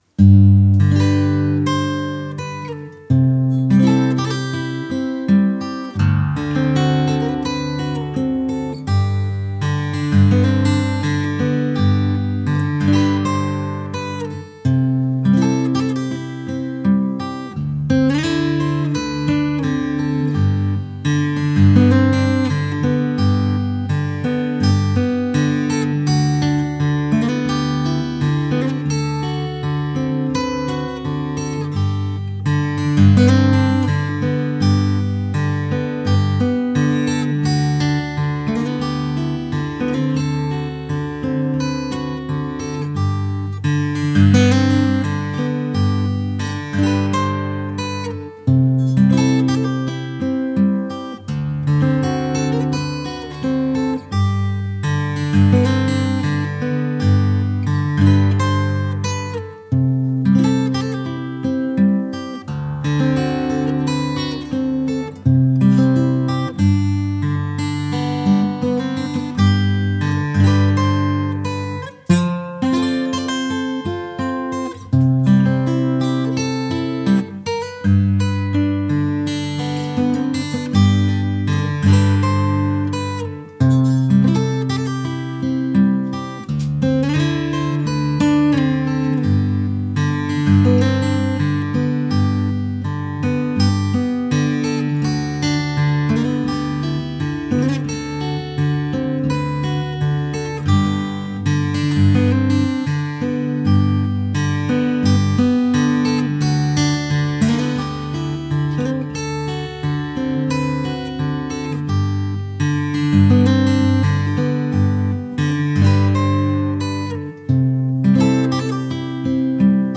Hawaiian Slack Key Guitar
Hawaiian Music